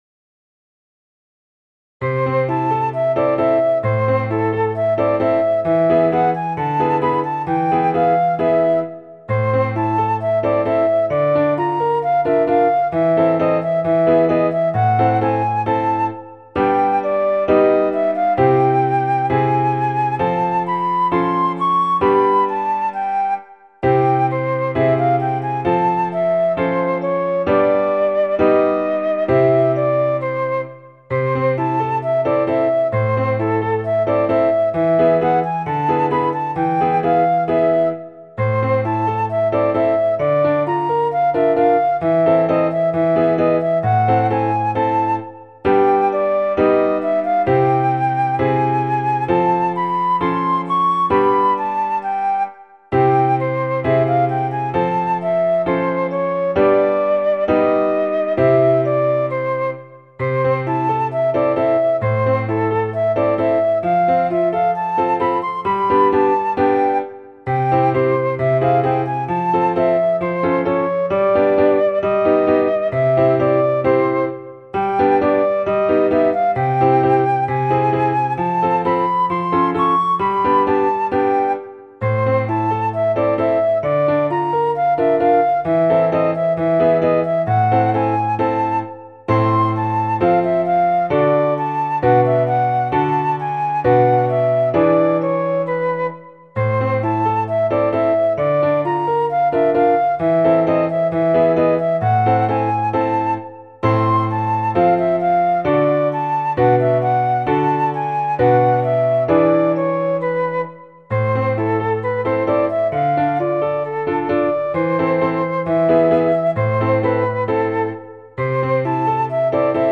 4曲の中で一番素直で明るい曲になったような気がします。
• 楽器：フルート、ピアノ
• 主調：イ短調
• 拍子：4/4拍子
• 速度：四分音符＝132
• 楽式：ソナタ形式